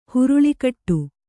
♪ huruḷi kaṭṭu